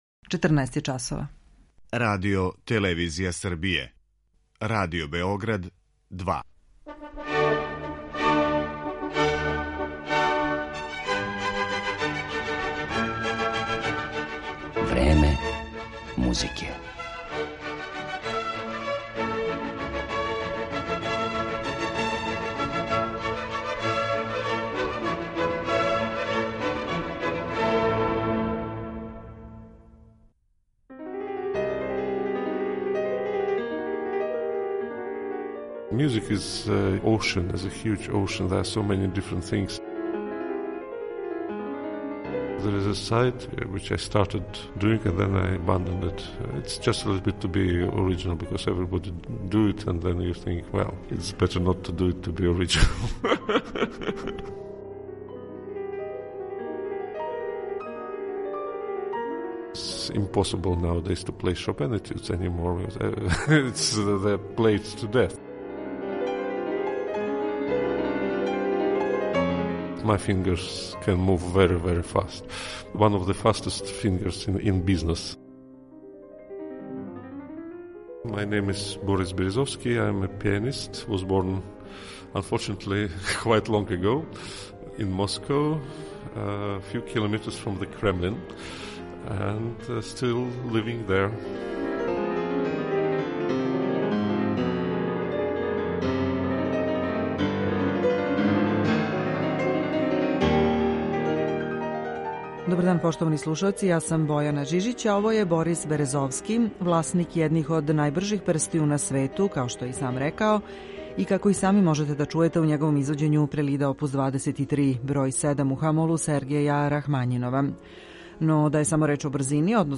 Славном руском пијанисти Борису Березовском посвећена је данашња емисија у којој ћемо га представити и кроз екслузивни интервју, снимљен са њим децембра 2019. године у Београду.